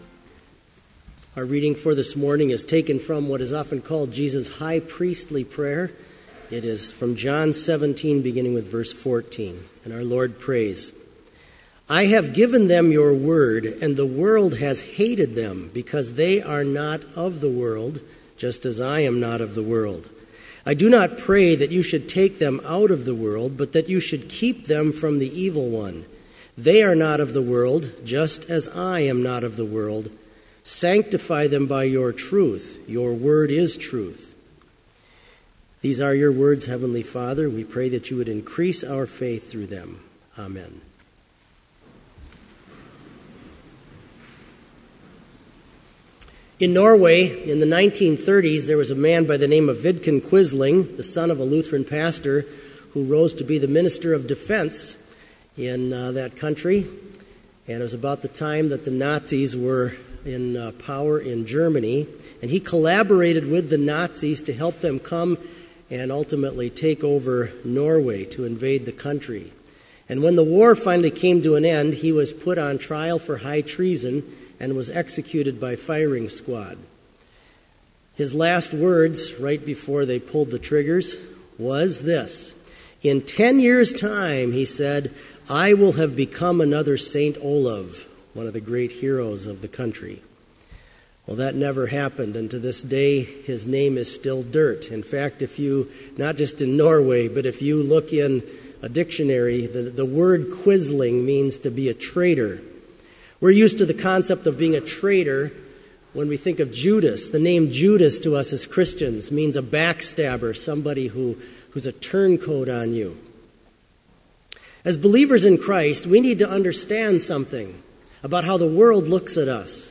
Complete Service
• Homily
This Chapel Service was held in Trinity Chapel at Bethany Lutheran College on Wednesday, April 29, 2015, at 10 a.m. Page and hymn numbers are from the Evangelical Lutheran Hymnary.